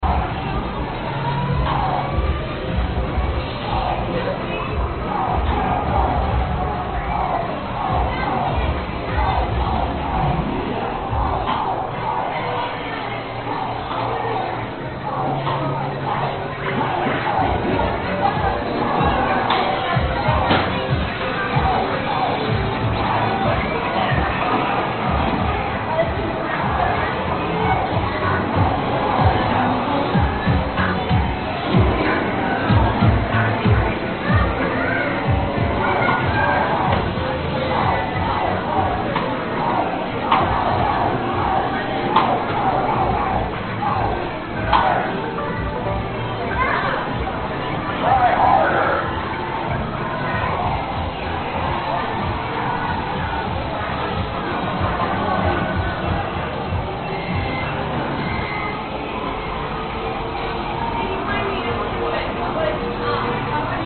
商场氛围
描述：我在当地一个5分钱的电玩城场地上进行的录音。
Tag: 街机 游戏 游戏 便士 视频_games